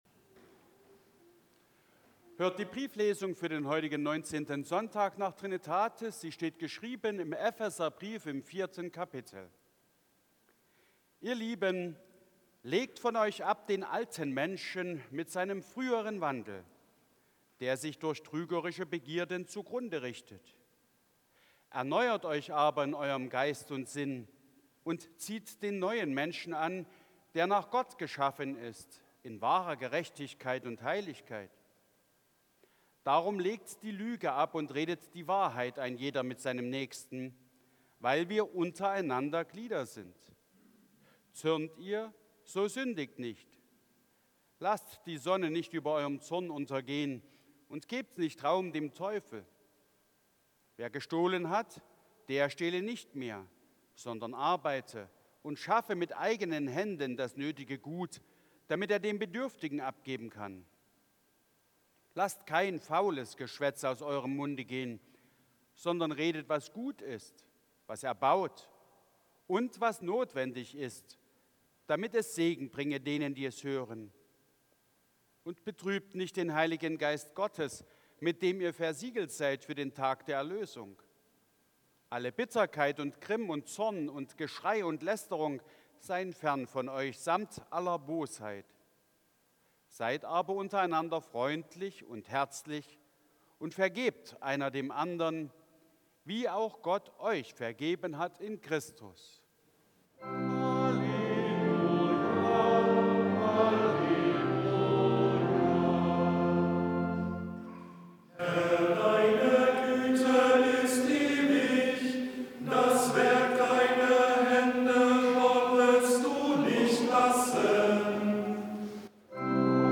4. Brieflesung aus Epheser 4,22-32 Ev.-Luth.
Audiomitschnitt unseres Gottesdienstes vom 19. Sonntag nach Trinitatis 2025.